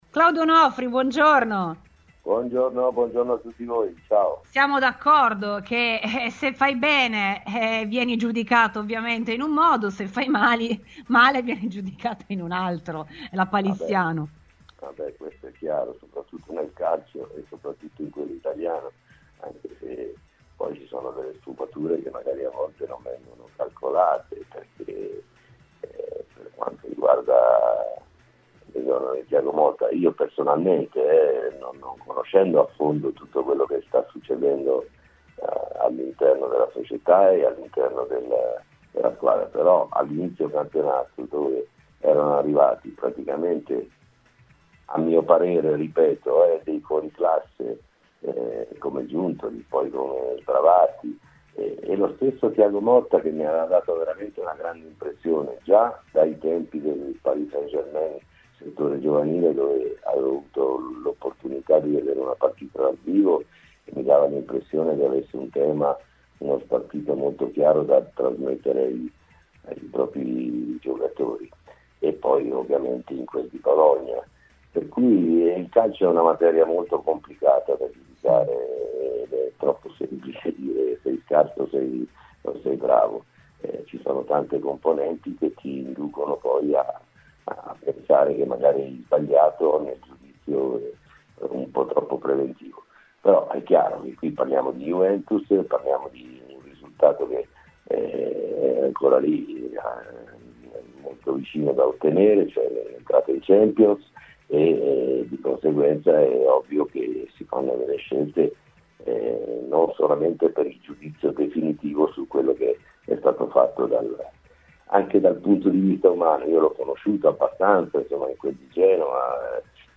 Ospite di Radiobianconera e della trasmissione RBN Cafè questa mattina, la storica bandiera del club rossoblu ha commentato così le recenti vicissitudini che hanno visto protagonista l'ex allentatore della Juventus.